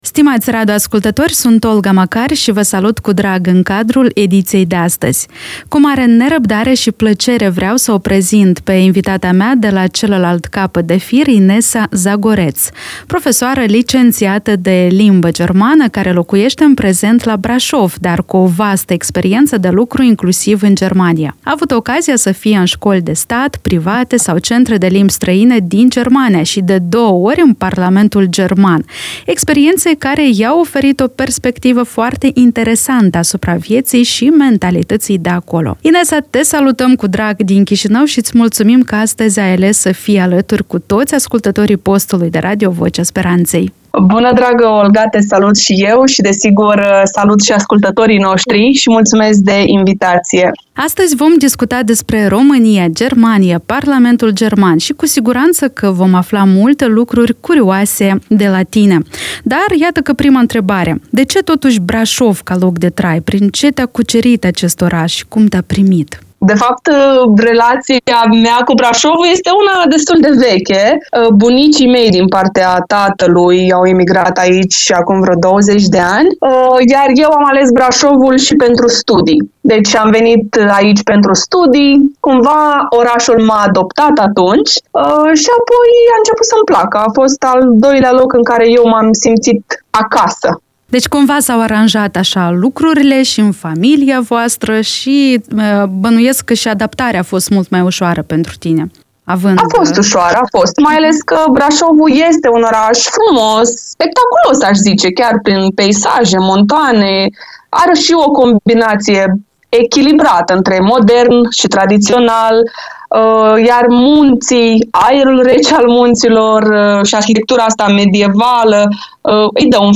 Vă invităm să audiați acest interviu motivațional cu moldoveanca noastră